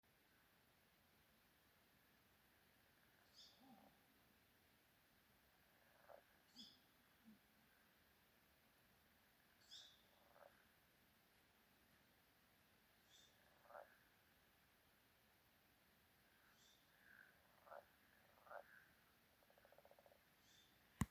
Urālpūce, Strix uralensis
Piezīmes/vismaz 2 pull balsis, ad neatbild.